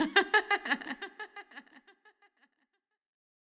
Banter Chant.wav